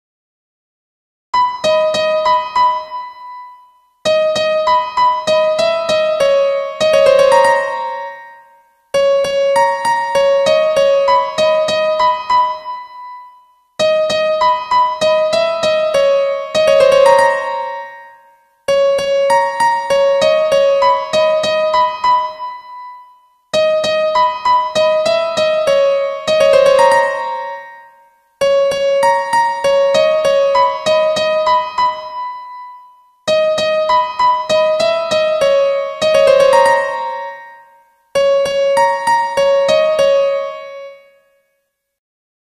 How to Play Piano Melody